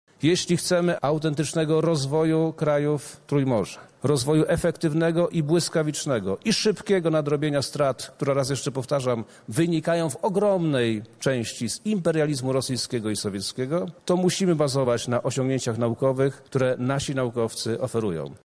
Podczas trwającego kongresu zostało podpisane porozumienie między 12 ośrodkami badawczymi w tej sprawie.
Podczas spotkania Minister Edukacji i Nauki Przemysław Czarnek zapowiedział również rozpoczęcie badań nad wpływem imperializmu rosyjskiego i wartości ważnych dla wspólnoty Trójmorza: